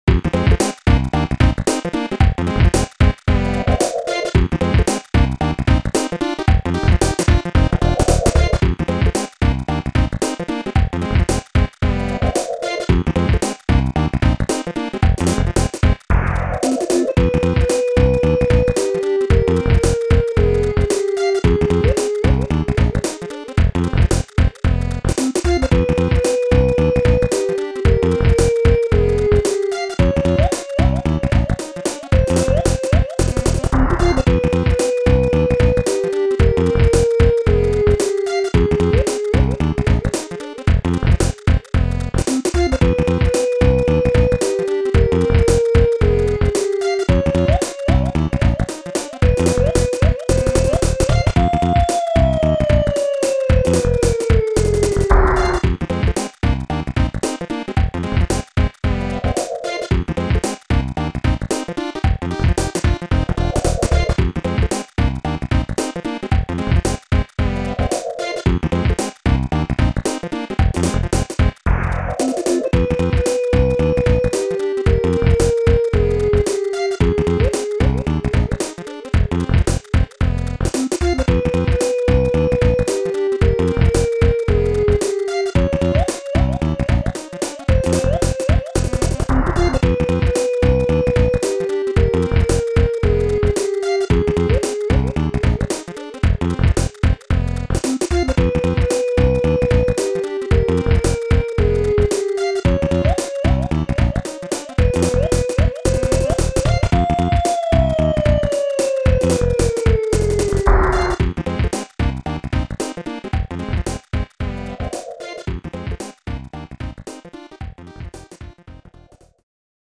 orchestral and rock tunes